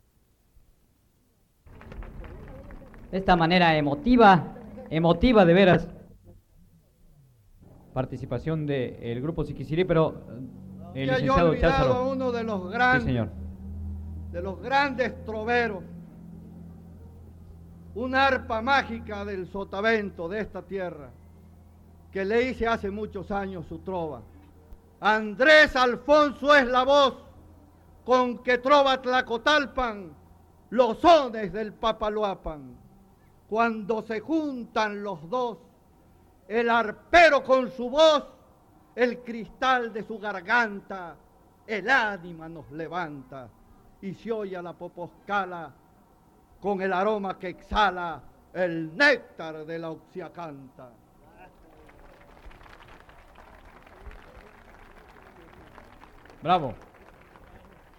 Noveno Encuentro de jaraneros